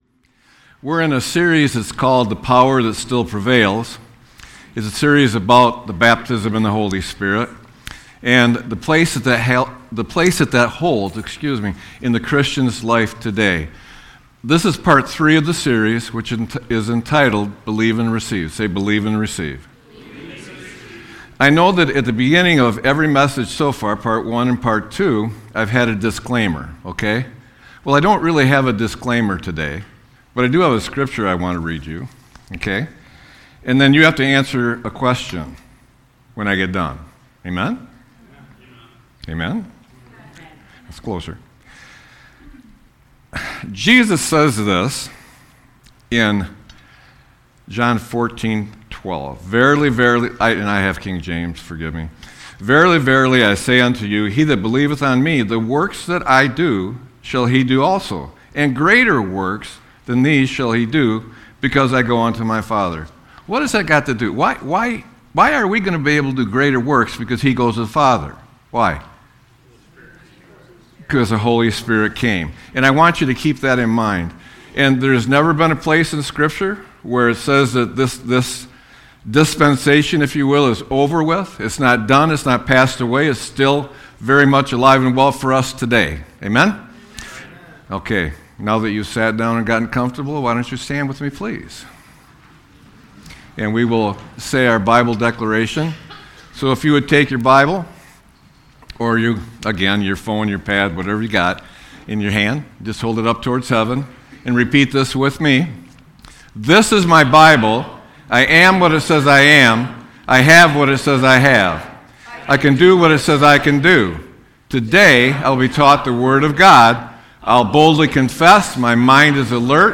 Sermon-2-08-26.mp3